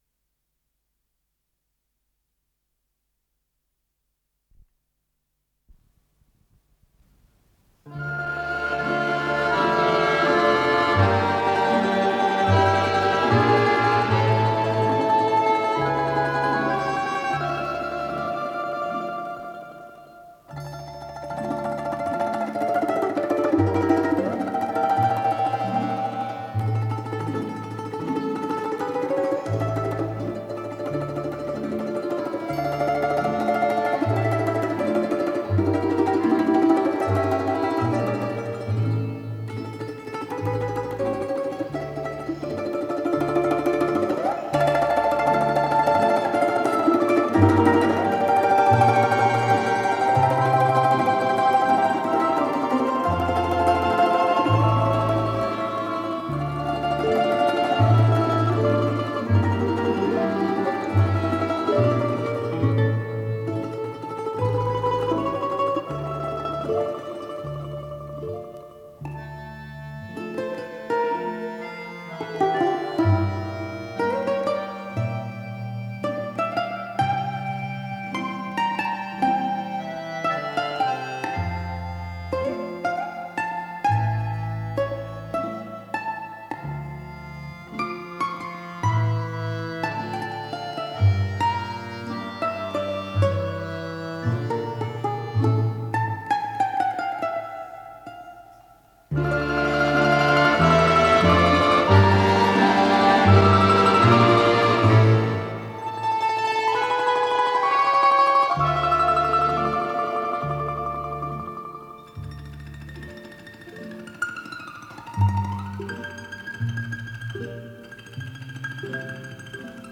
с профессиональной магнитной ленты
ИсполнителиИнструментальный ансамбль Государственного Красноярского танца Сибири
балалайка